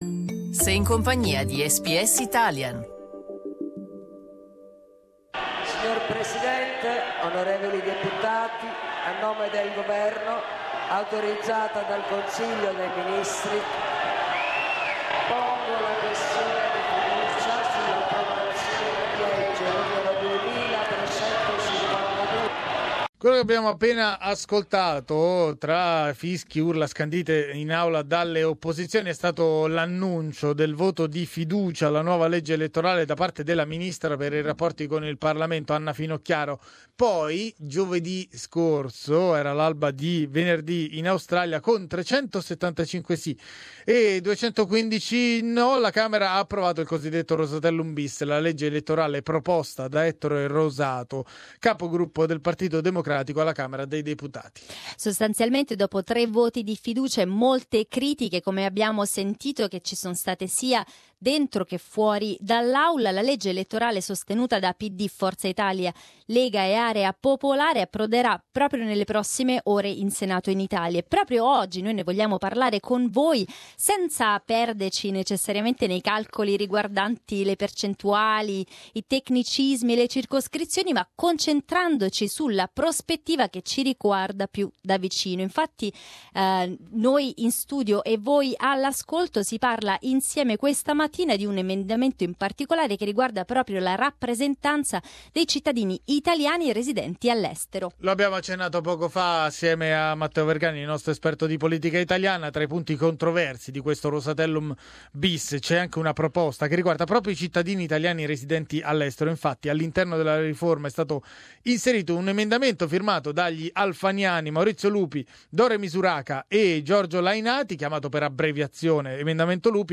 Vi sentireste ugualmente rappresentati da un parlamentare che non risiede down under? Ci rispondono gli ascoltatori.